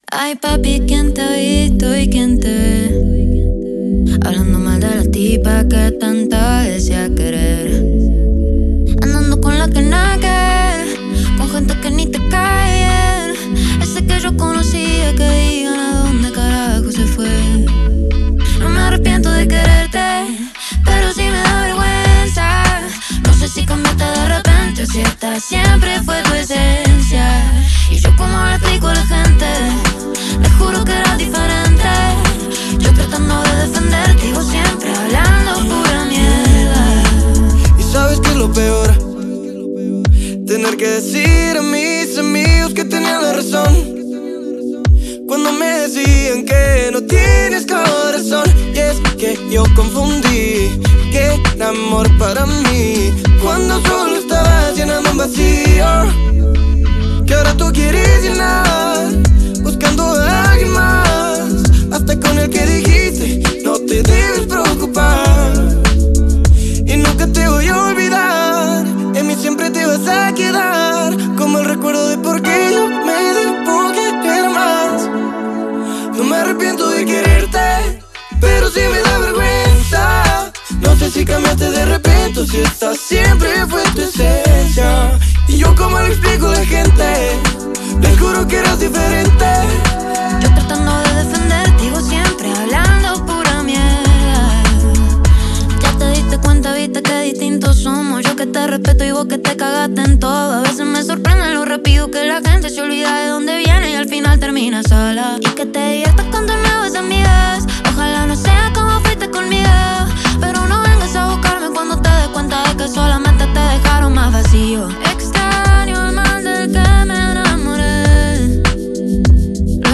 sino porque se sumaron beats del funk.